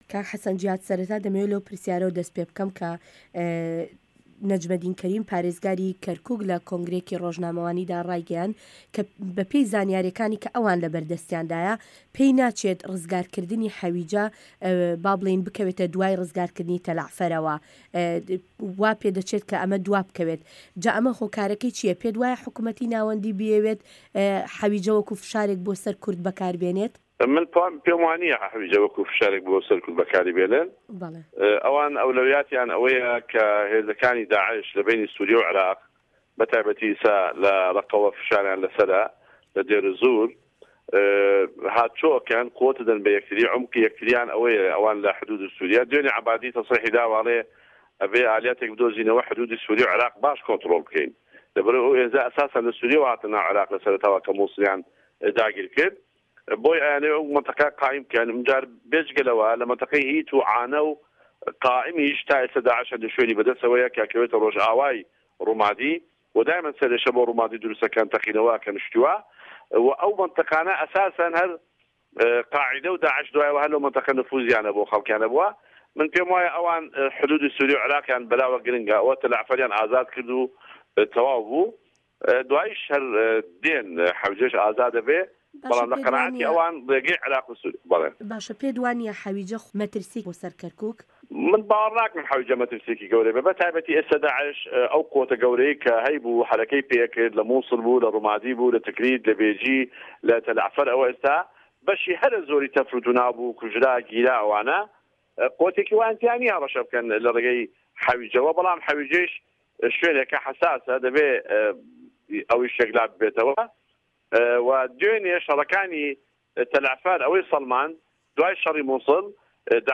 وتووێژ لەگەڵ حەسەن جیهاد